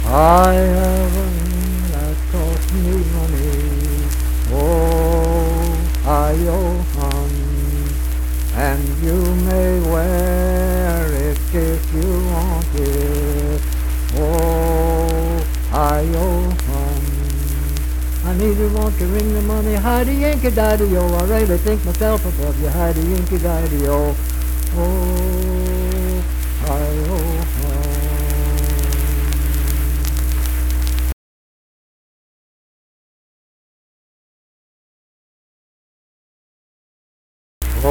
Unaccompanied vocal music
Dance, Game, and Party Songs
Voice (sung)
Webster County (W. Va.)